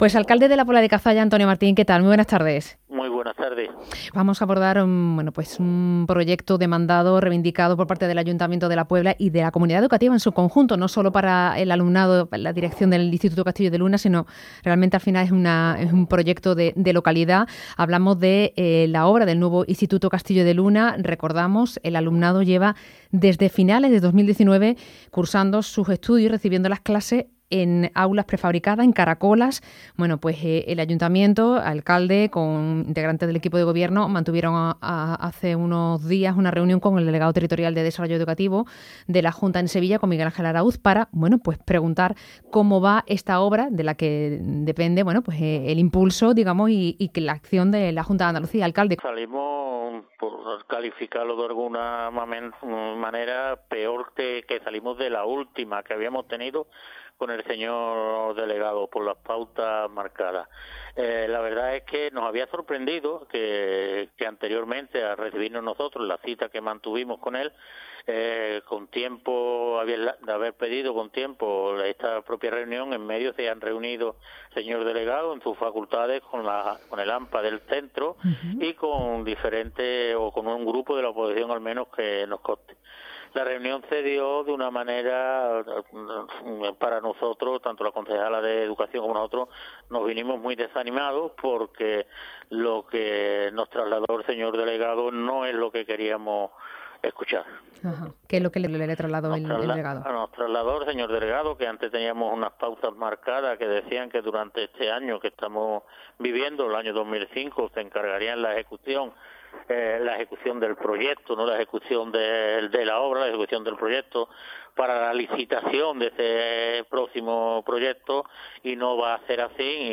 Entrevista | Antonio Martín, alcalde de La Puebla de Cazalla - Andalucía Centro